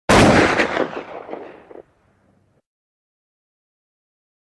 AK-47 Gunshot Sound